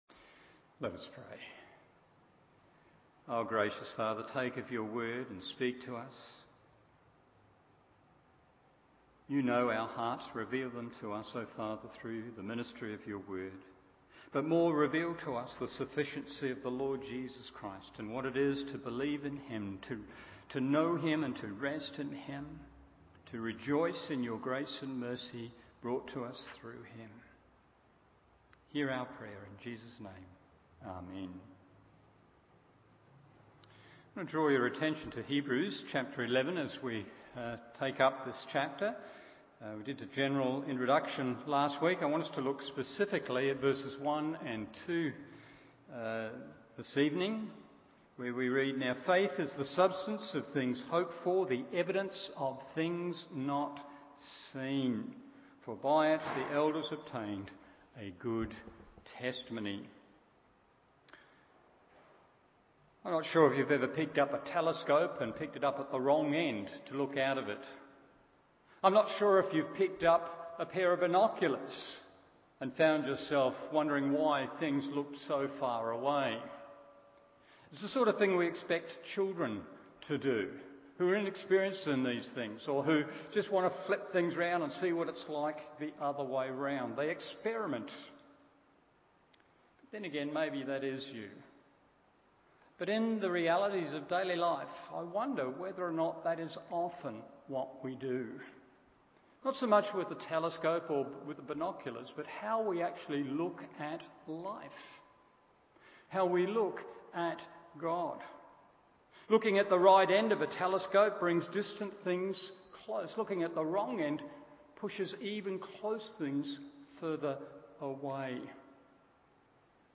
Evening Service Hebrews 11:1-12:2 1.